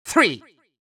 countIn3Far.wav